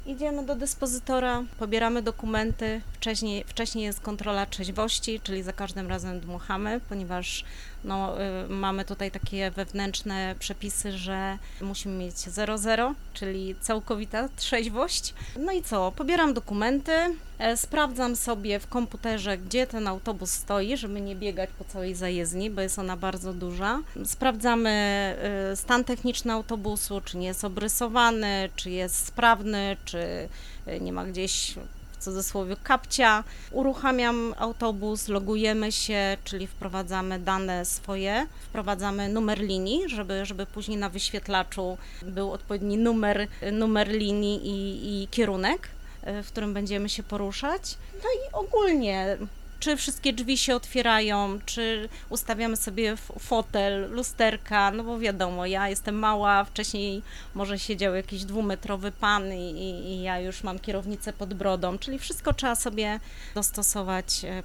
Radio Rodzina odwiedziło zajezdnię autobusową przy ul. Obornickiej i porozmawiało o tych i innych tematach z pracownikami MPK Wrocław.